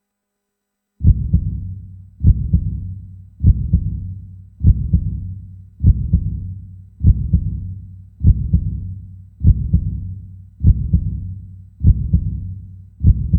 Dramatic Heartbeat 2 Sound Effect Free Download
Dramatic Heartbeat 2